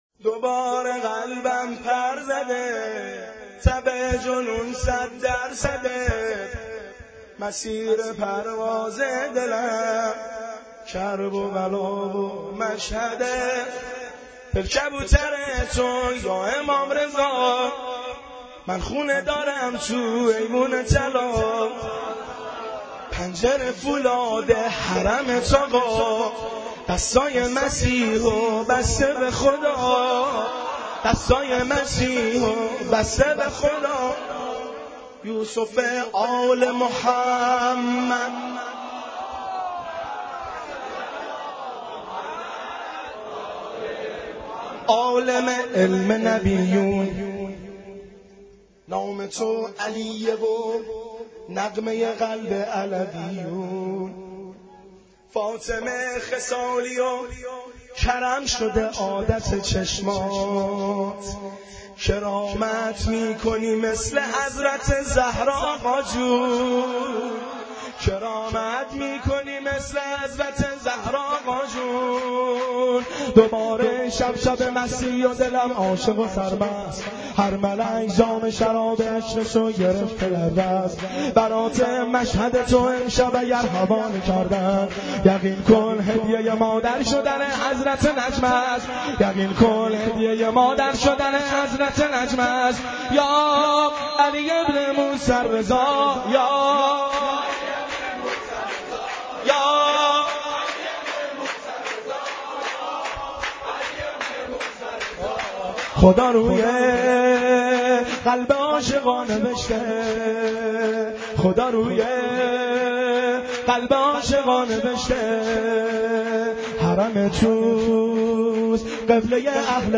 گلچین جدیدترین و بهترین مولودی های امام رضا